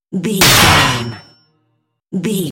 Dramatic hit deep metal clicnk
Sound Effects
heavy
intense
dark
aggressive